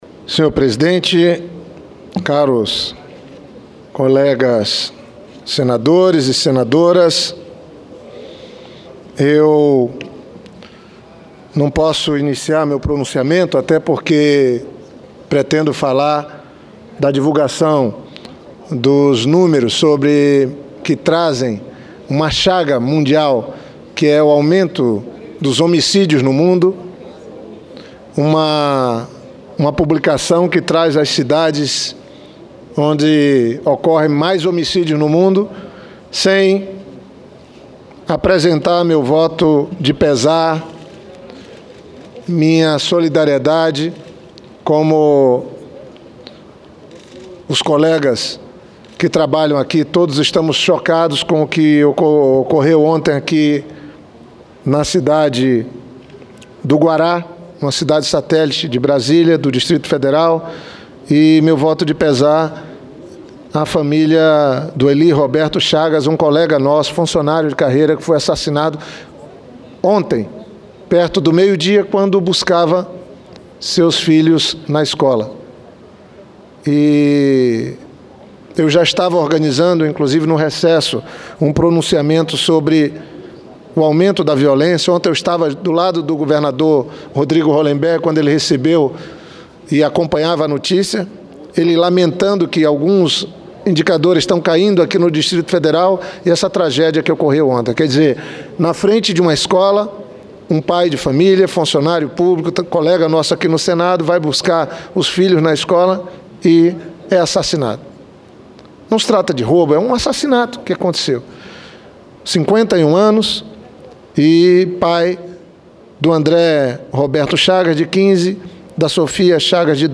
Plenário
Discursos